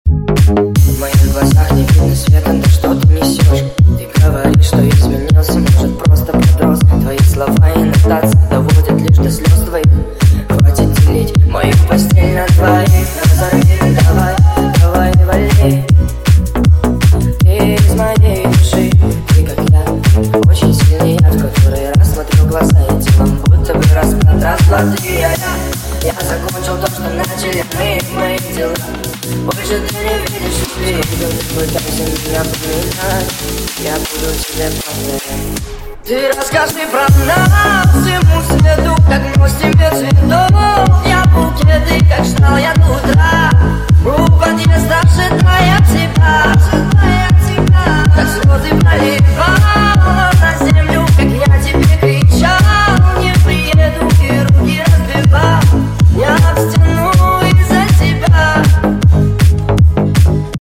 поп
мужской голос
ритмичные
энергичные
цикличные